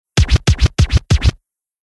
На этой странице собраны аутентичные звуки виниловых пластинок: характерные потрескивания, теплый аналоговый звук и шумы, создающие особую атмосферу.
Пластинку заклинило